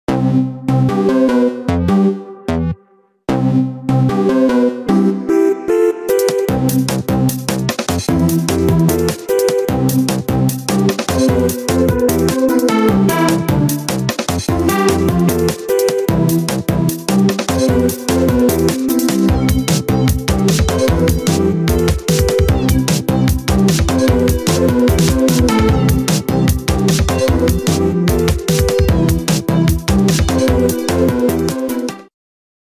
Samsung Galaxy A15 Ringtone